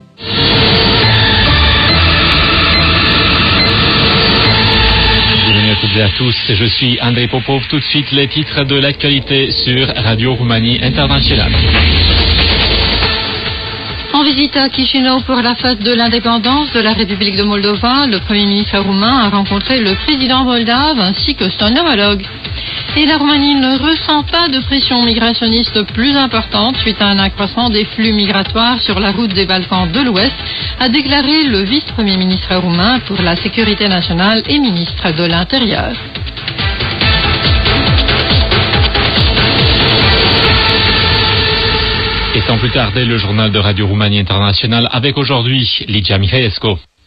However this is the first radio that I have used with DRM support, and I have been amazed with its quality and features.
Here is a sample of Radio Romania:
drmexampleradioromania.m4a